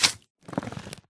mob / magmacube / jump2.ogg
jump2.ogg